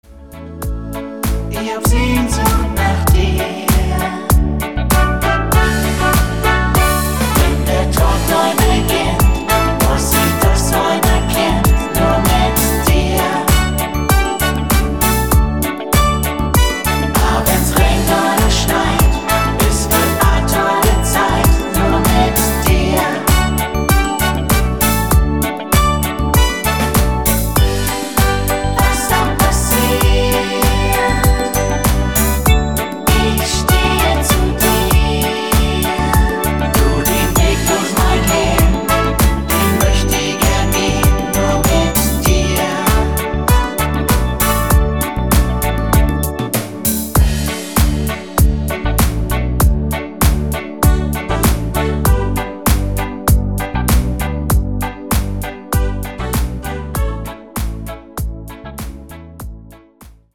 Rhythmus  8 Beat Reggae